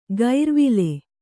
♪ gair vile